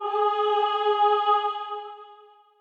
admin-fishpot/b_choir3_v127l8-9o5gp.ogg at main